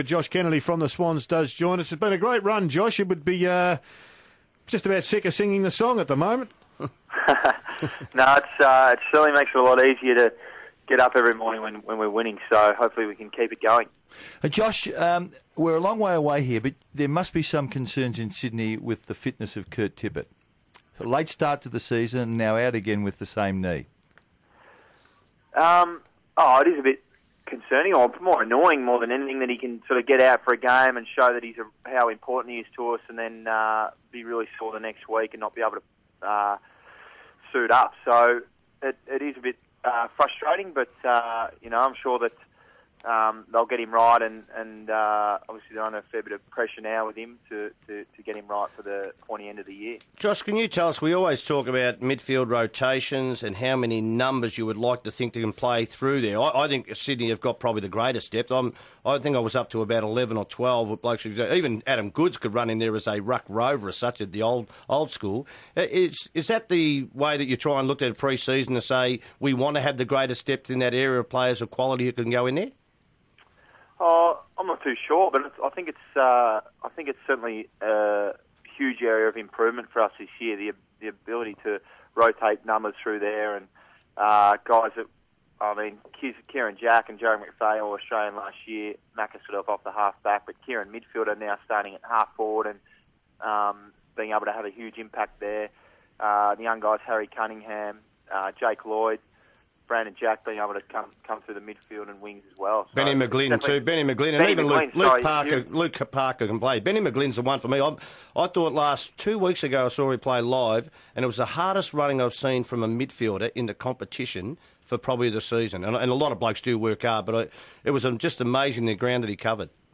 Swans midfielder Josh Kennedy appeared on 3AW with Gerard Healy, Tony Shaw and Mike Sheahan on Thursday July 11, 2014